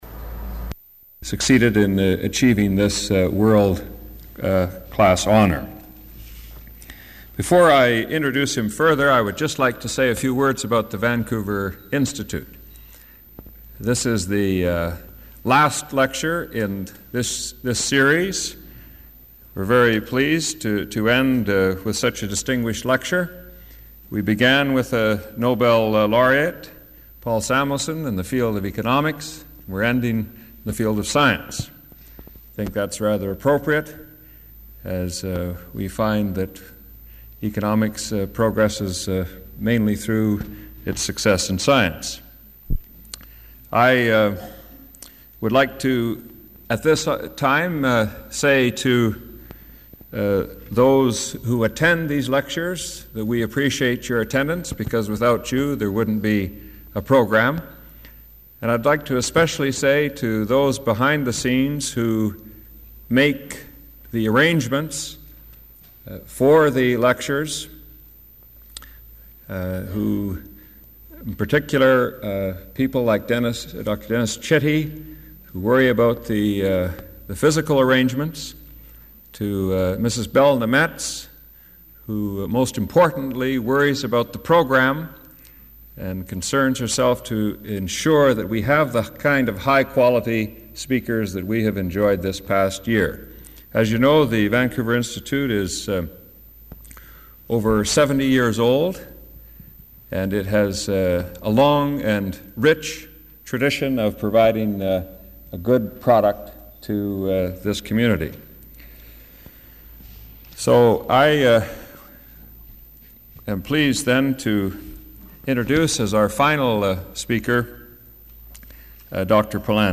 Item consists of a digitized copy of an audio recording of a Cecil and Ida Green Lecture delivered at the Vancouver Institute by John Polanyi on March 28, 1987.